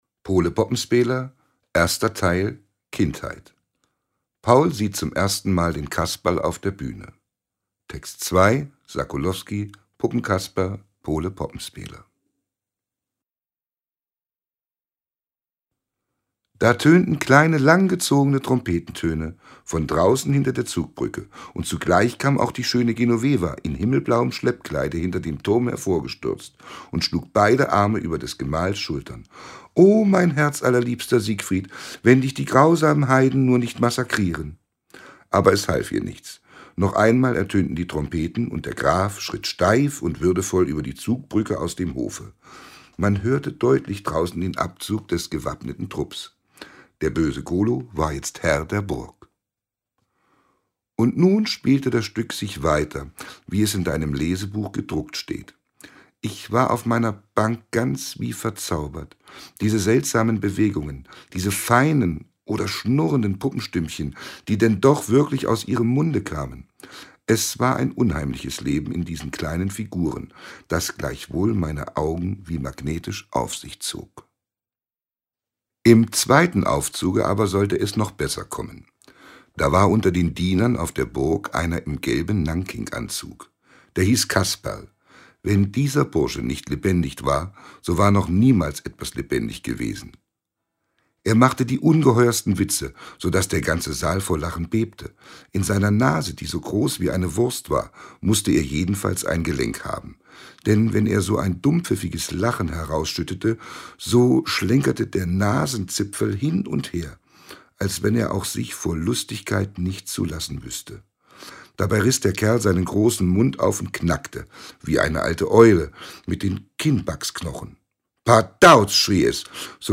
Audioguide zur Ausstellung
Text: Theodor Storm, Ausschnitt aus „Pole Poppenspäler“ in „Sämtliche Werke“ (1877-1889; 1898)